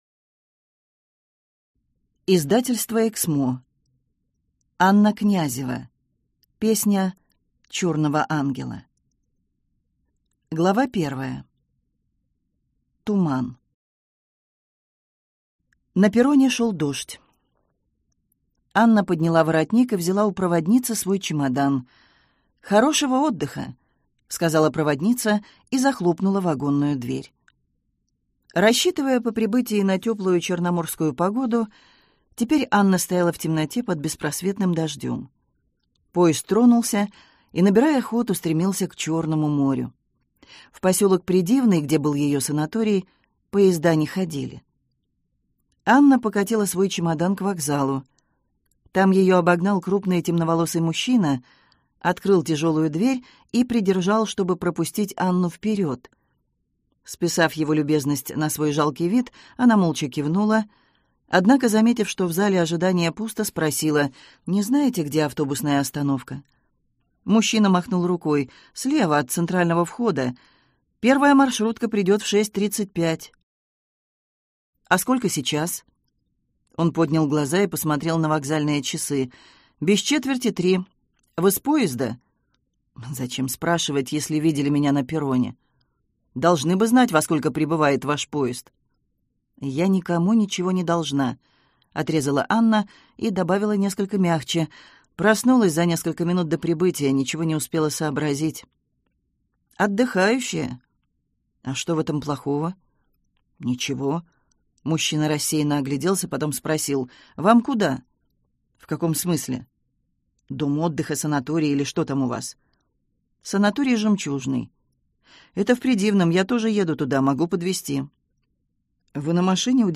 Аудиокнига Песня черного ангела | Библиотека аудиокниг